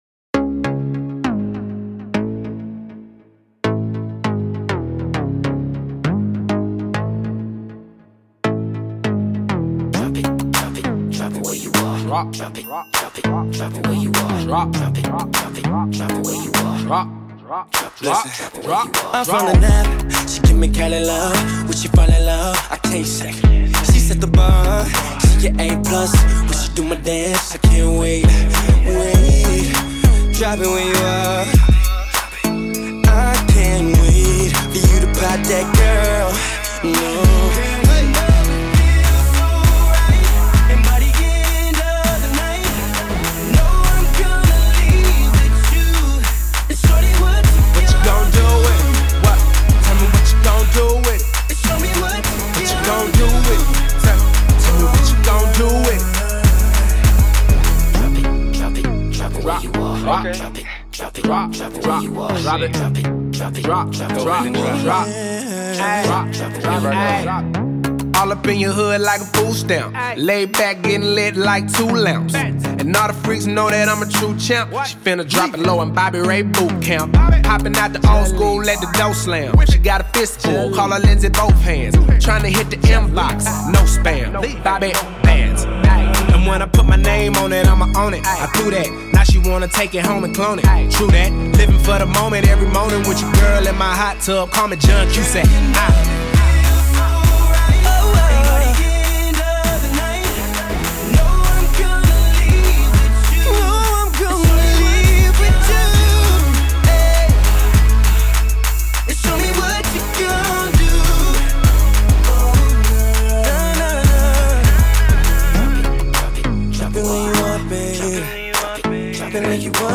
HipHop 2010er